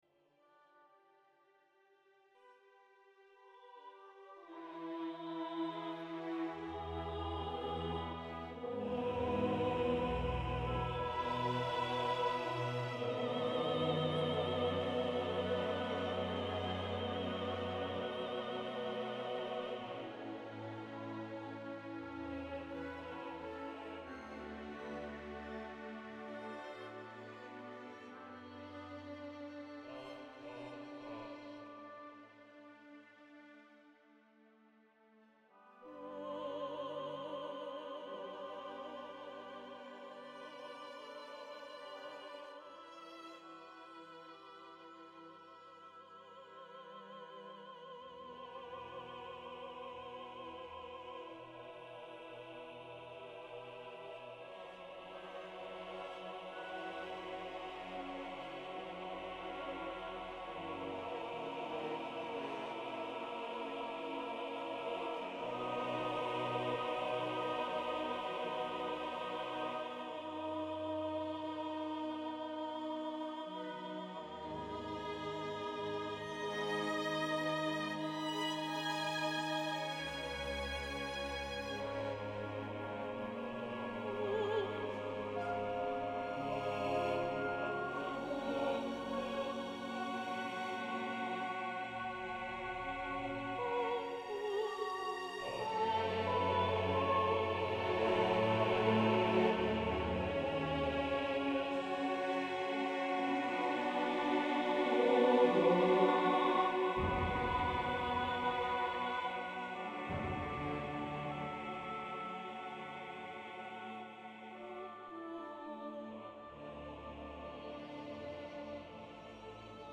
Veda Opera University - Lectures for Harmony and Enlightenment
Maharishi_Mahesh_Yogi_Natural_Dos_and_Donts_London_1965.mp3